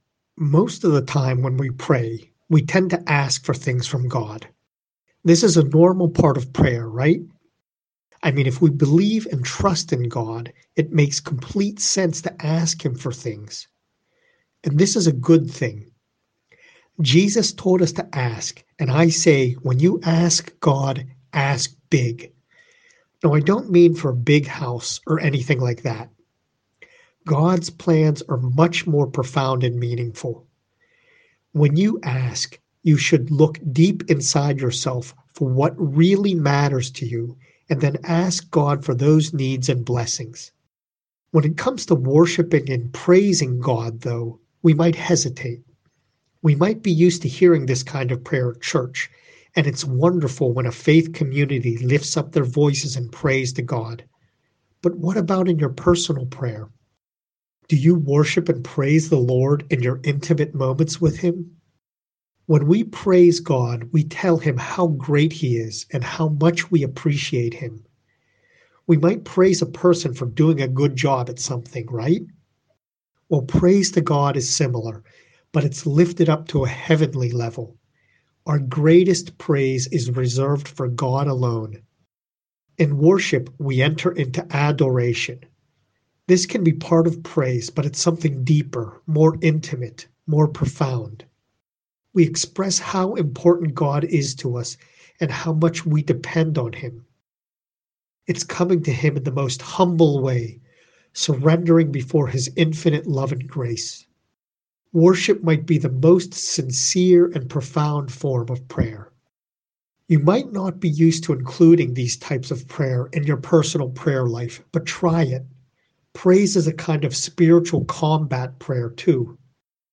prayer-of-praise-and-worship.mp3